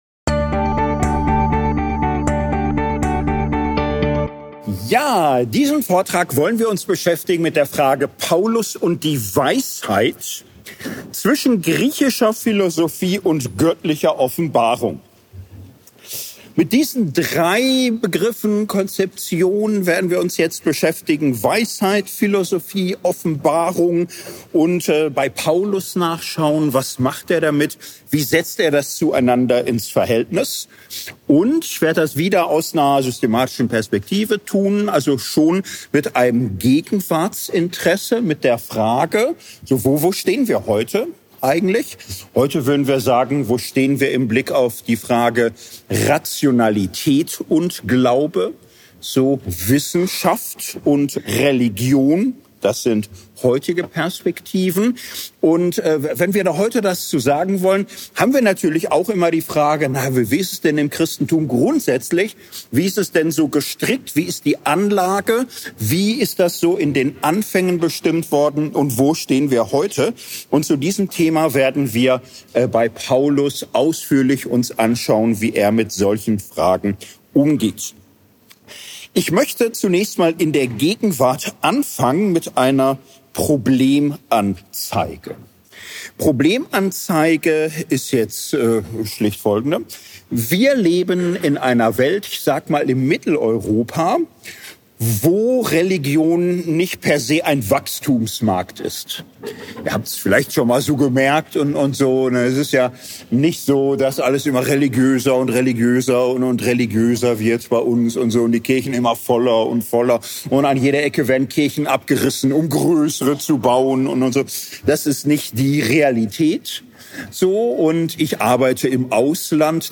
Worthaus 13 – Tübingen: 8. Juni 2025